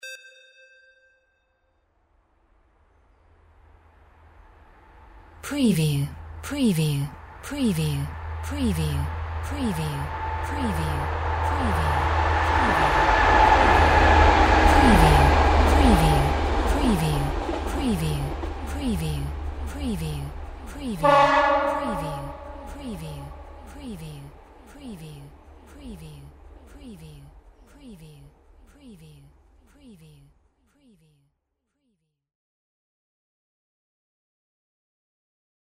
Train small passby horn
A small shuttle diesel type train passing through a valley.
Stereo sound effect - Wav.16 bit/44.1 KHz and Mp3 128 Kbps
previewTRAN_TRAIN_SMALL_PASSBY_WBHD01.mp3